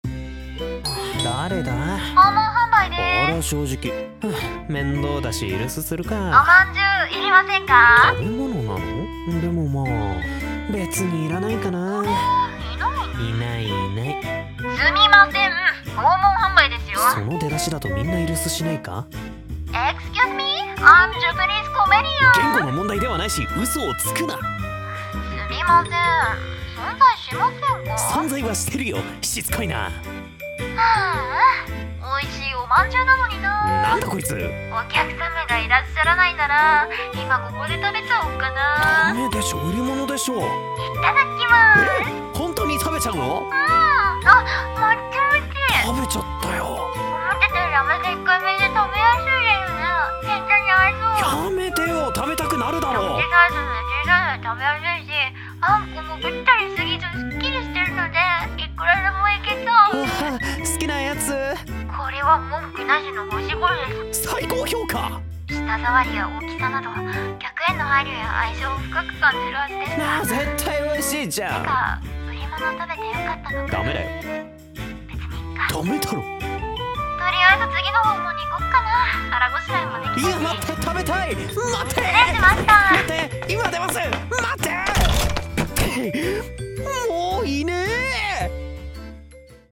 【ギャグ声劇】